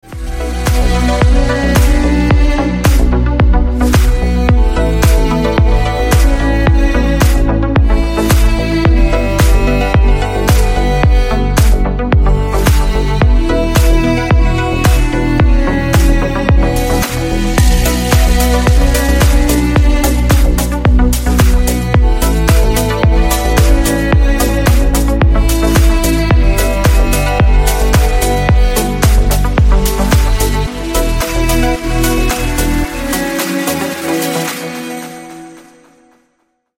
Танцевальные рингтоны
Громкие рингтоны